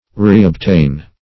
Reobtain \Re`ob*tain"\ (r?`?b-t?n"), v. t. To obtain again.